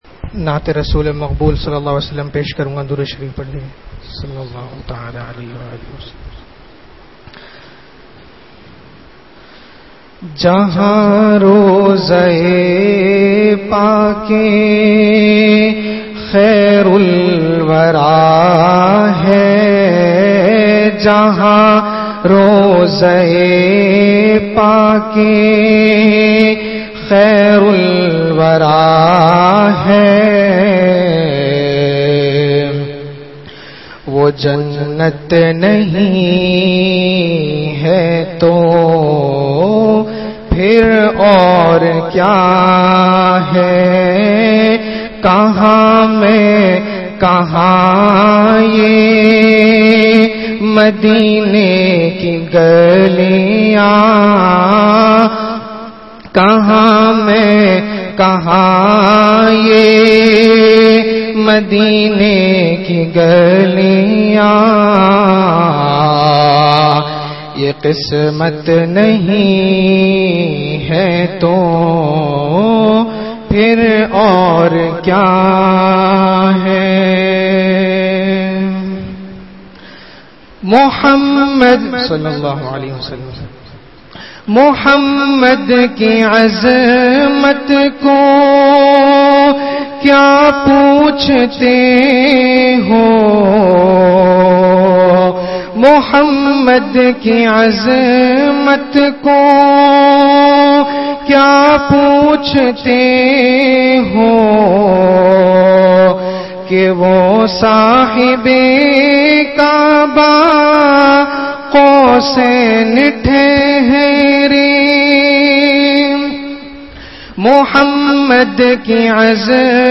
Bayanat